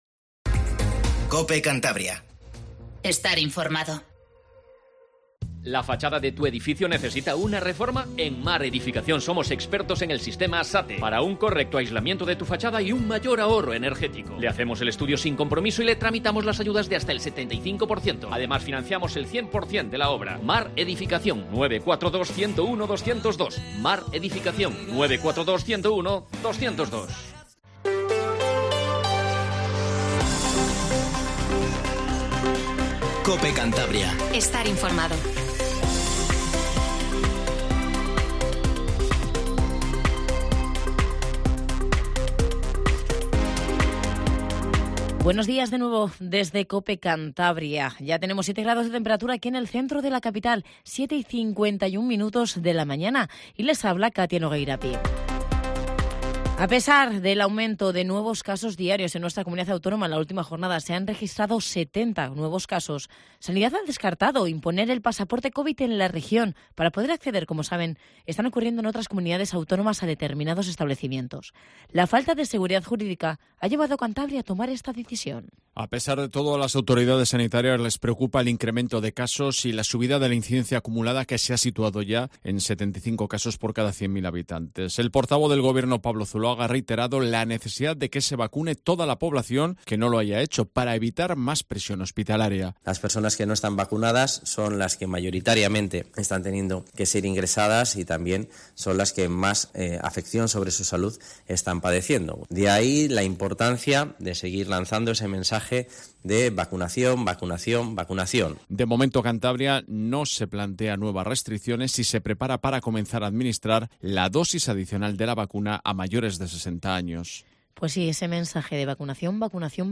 Informativo Matinal COPE CANTABRIA